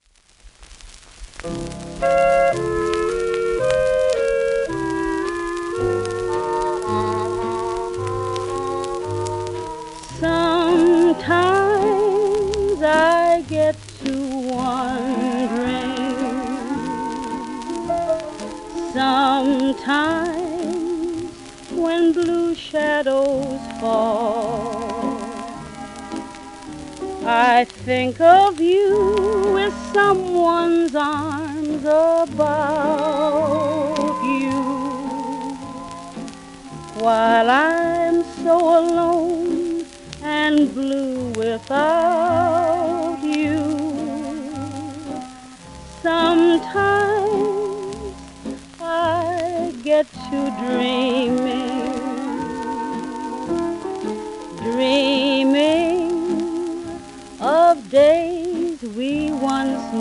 w/オーケストラ
盤質:B+ *面擦れ,キズ
1942年頃の録音
1930年代から40年代にかけて人気を博した白人ジャズシンガー